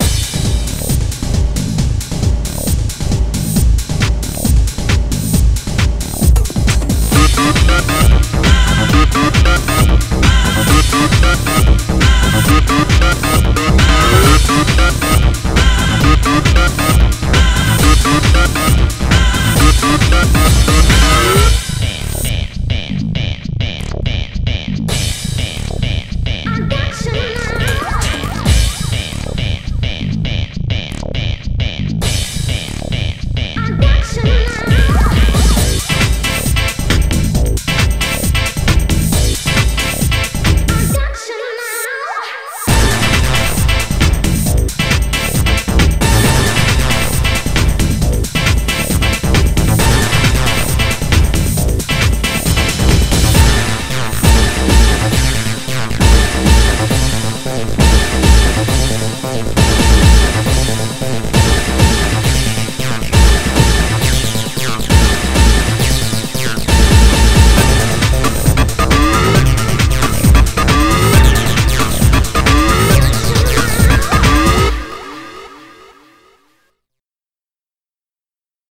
BPM135